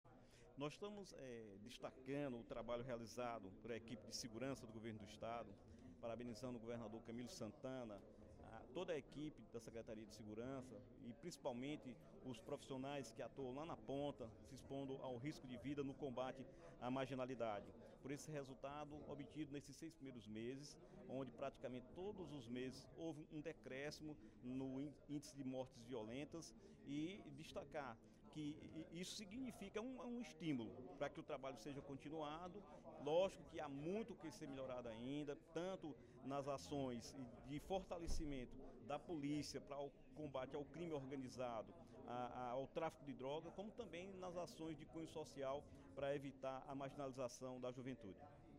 “Quero parabenizar o governador Camilo Santana, toda a equipe da Secretaria de Segurança, e, principalmente, os profissionais que atuam lá na ponta, se expondo ao risco de vida no combate à marginalidade”, disse, em pronunciamento no primeiro expediente da sessão plenária.